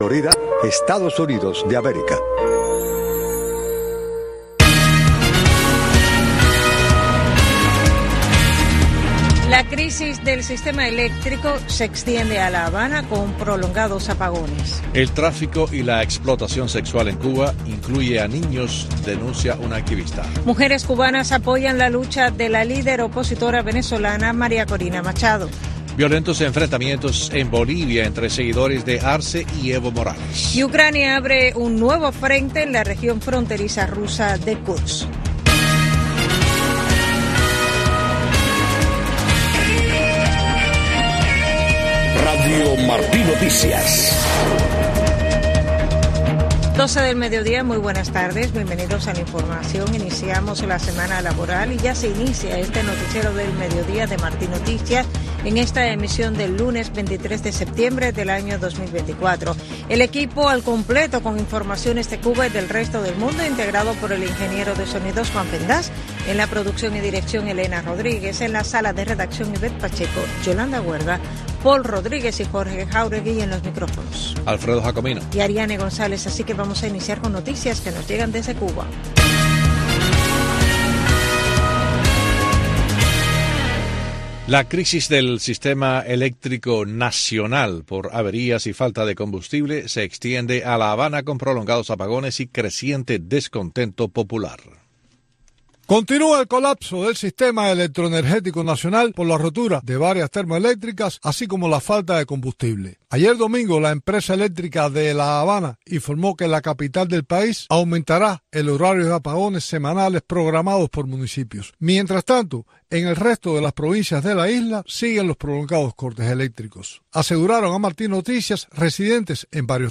Noticiero de Radio Martí 12:00 PM | Primera media hora